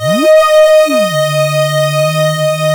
OSCAR 10 D#5.wav